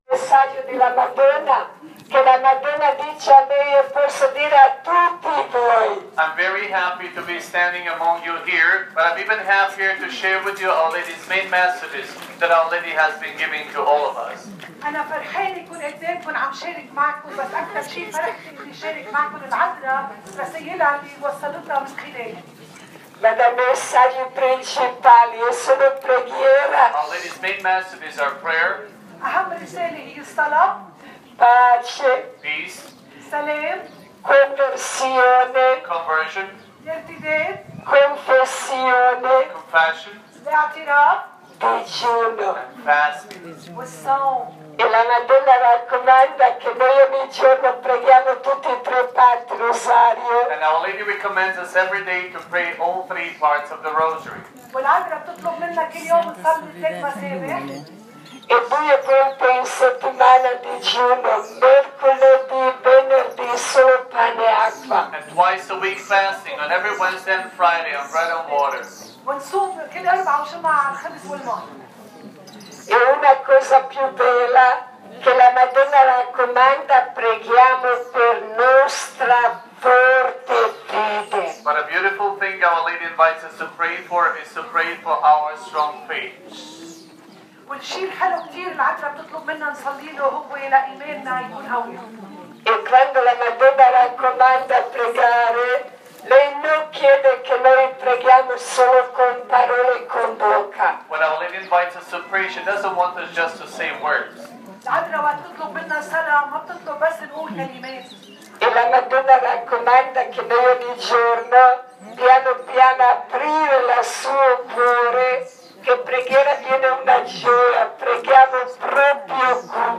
Pellegrinaggio a Medugorje del 15-20 maggio 2015 Lunedì 18 maggio
TESTIMONIANZA
presso la comunità delle Sorelle della Famiglia Ferita